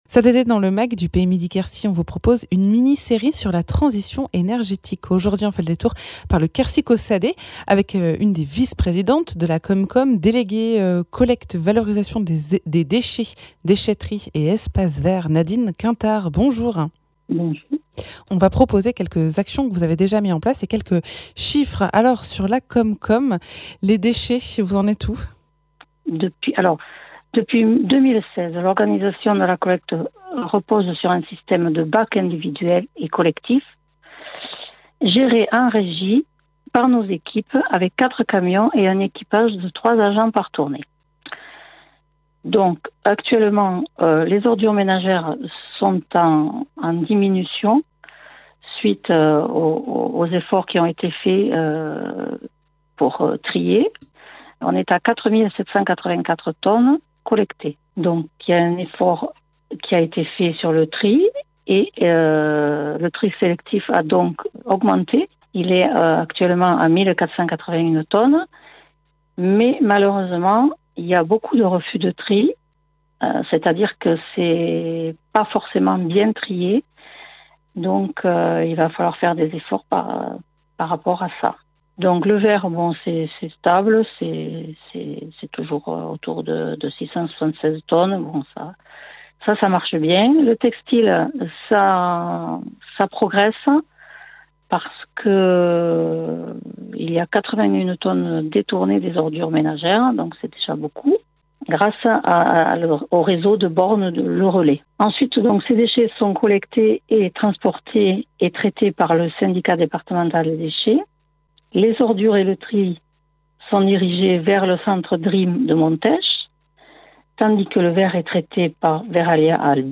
Invité(s) : Nadine Quintard, vice présidente de la com-com du Quercy Caussadais et déléguée collecte, valorisation des déchets, déchetteries, espace vert